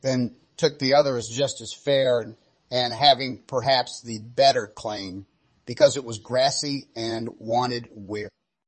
tortoise-tts - (A fork of) a multi-voice TTS system trained with an emphasis on quality